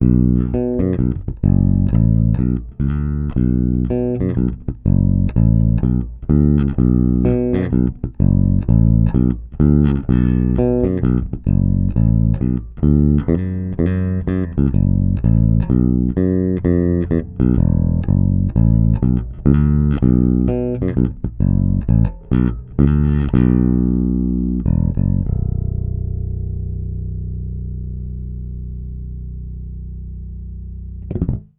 Nahrávky jsou bez kompresoru, linka do zvukovky.
Trochu je slyšet brum, ale to mi dělá dnes elektrika doma.